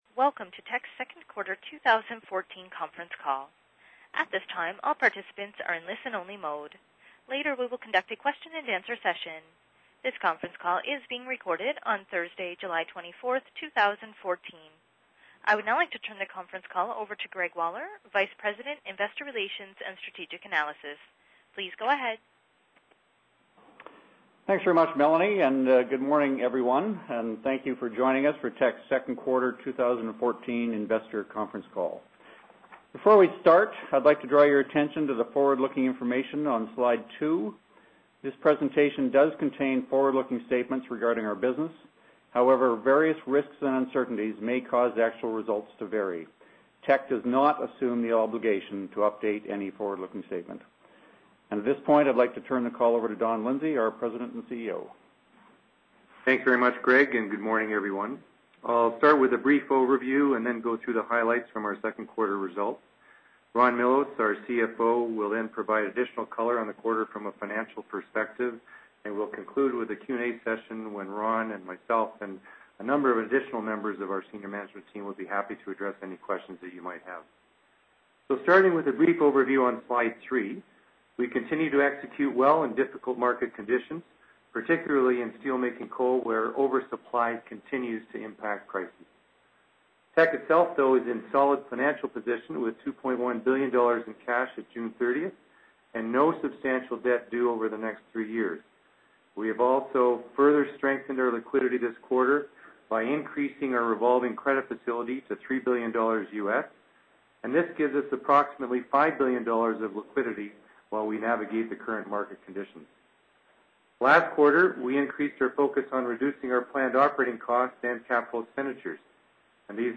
q2_2014_conference_call_audio.mp3